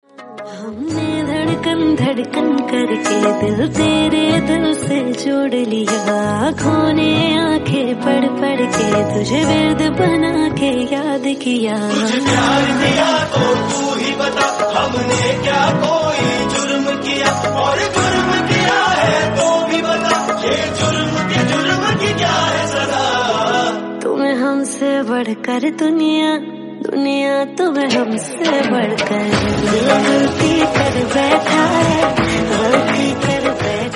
soulful and melodious tune